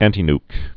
(ăntē-nk, -nyk, ăntī-)